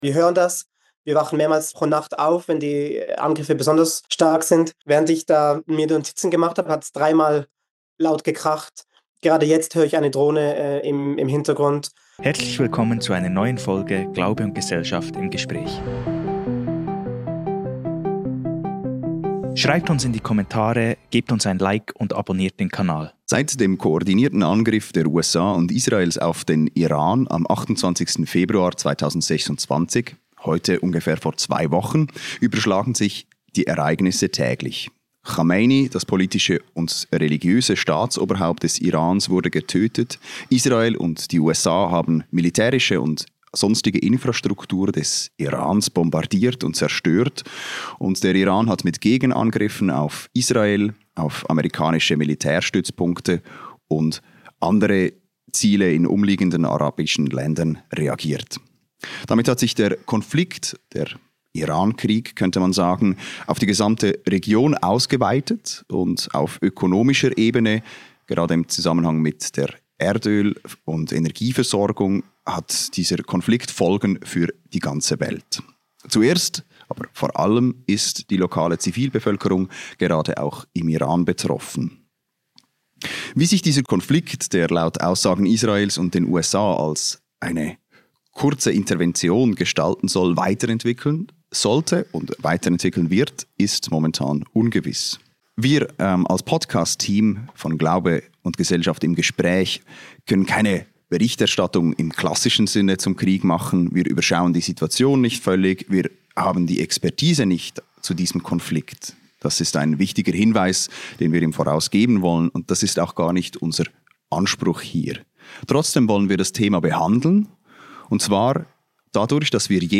220. Gespräch zur Situation im Libanon ~ Glaube und Gesellschaft Podcast